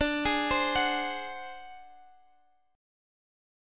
Listen to D7b5 strummed